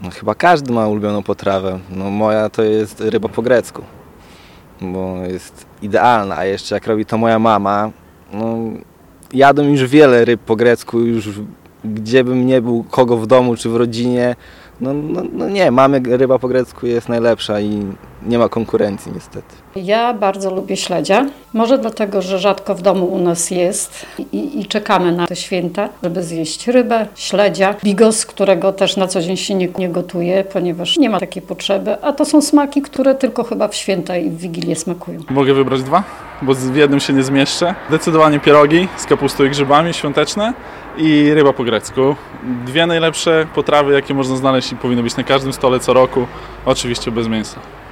Zapytał o to nasz reporter.